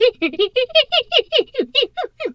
pgs/Assets/Audio/Animal_Impersonations/hyena_laugh_01.wav at master
hyena_laugh_01.wav